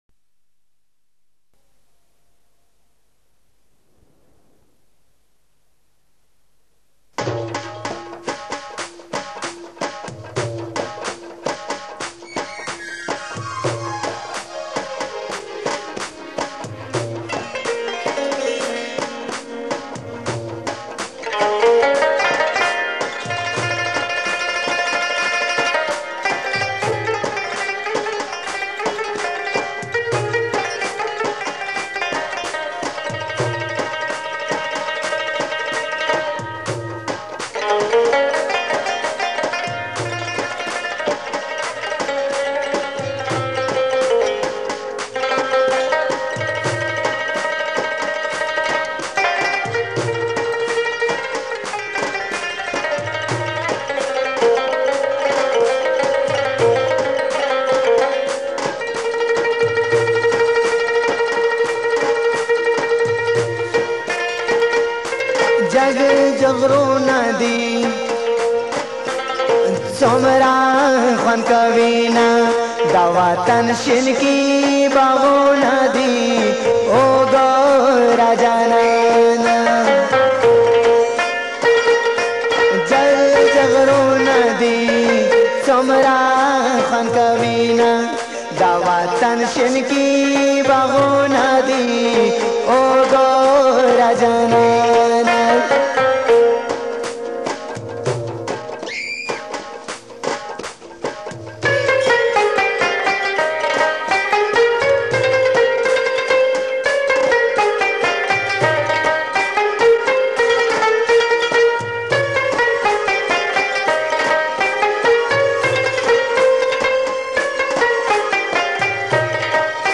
Attan